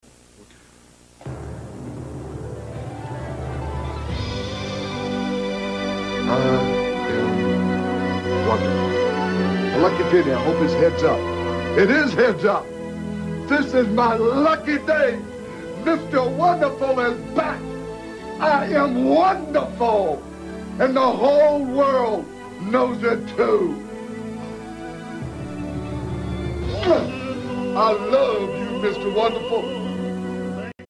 Orndorff’s legendary music swells….and all is once again right with the world.